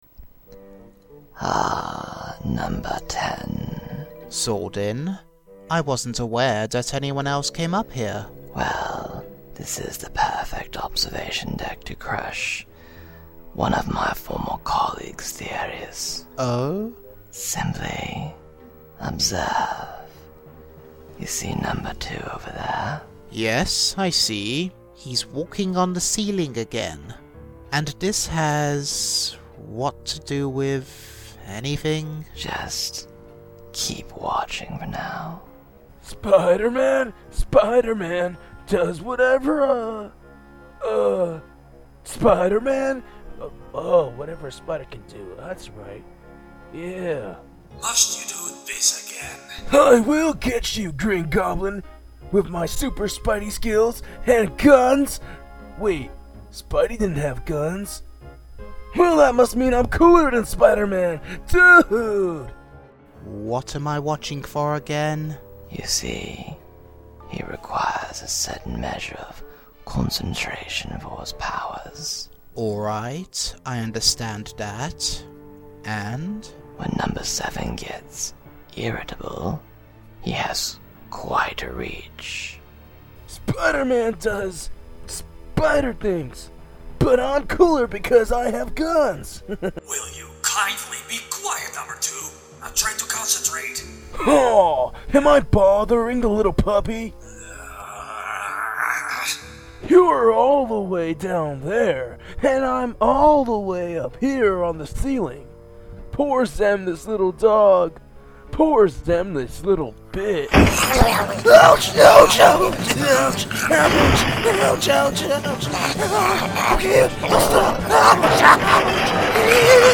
Short Dramas